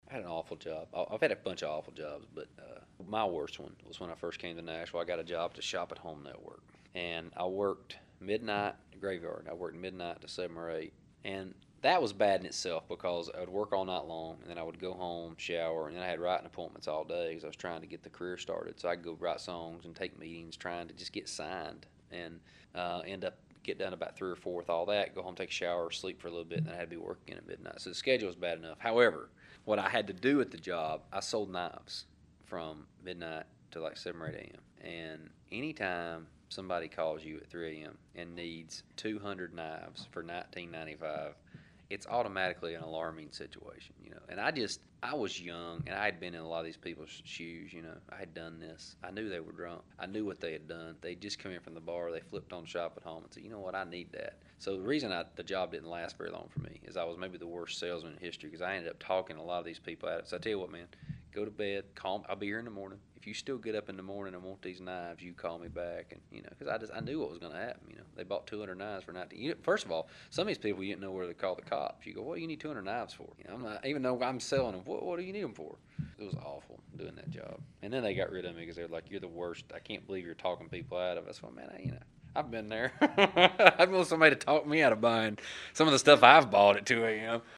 Audio / Eric Church talks about one of his worst job.